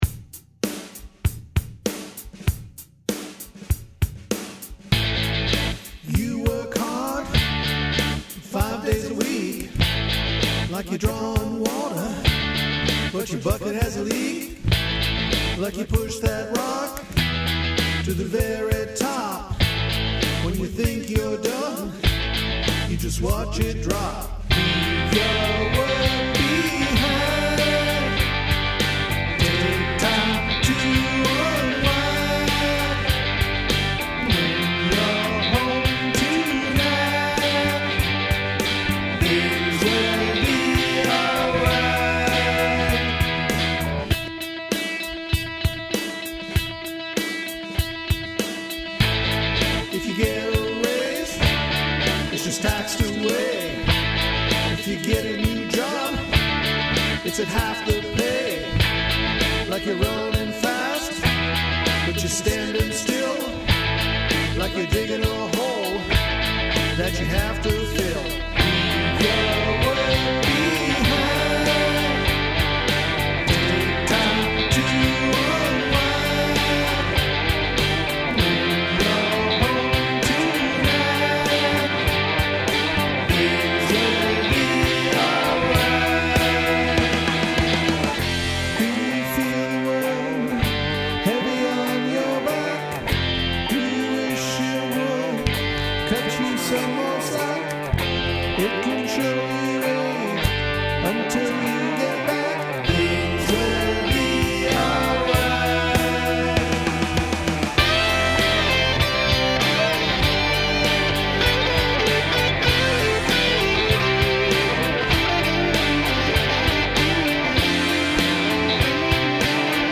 So, with that theme in mind, here’s another demo celebrating everyone with a day job.